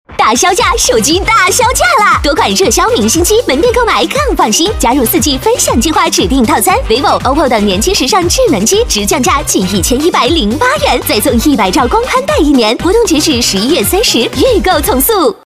女国126_广告_促销_天翼活动篇_活力.mp3